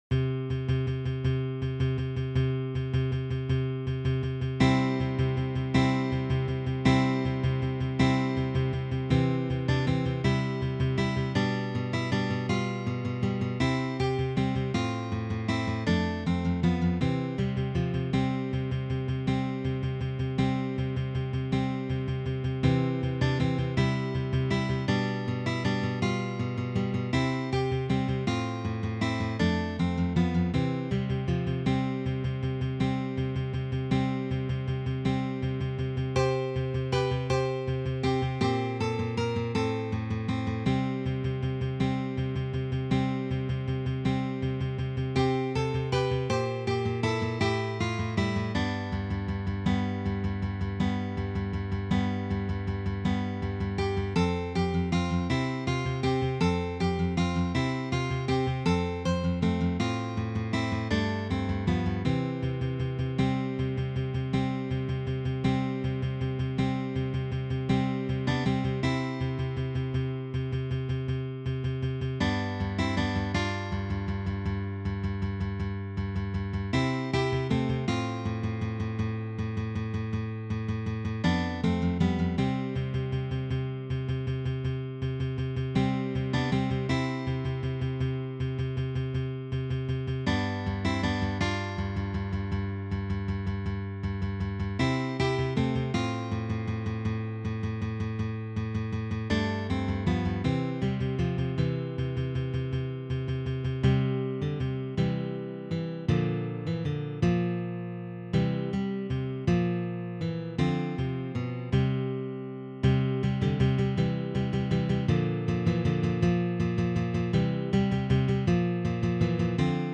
arranged for three guitars